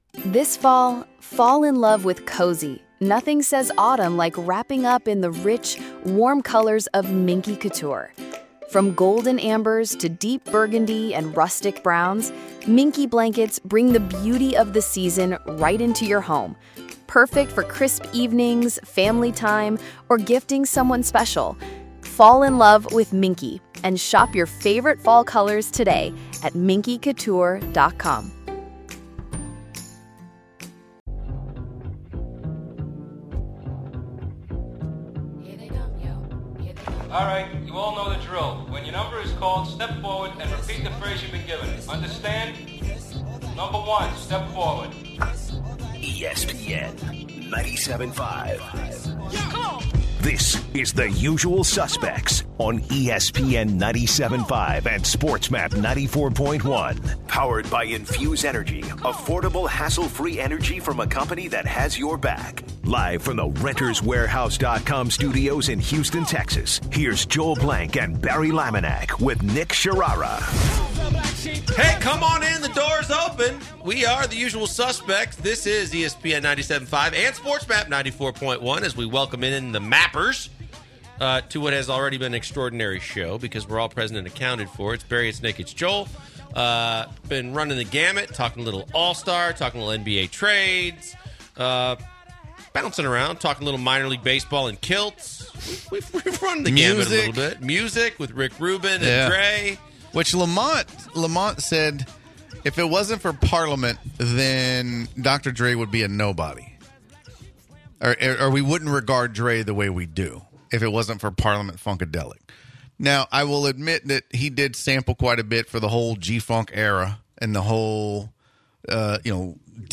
the final hour tops with the guys getting a betting ring going with callers.